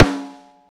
rim snare ff.wav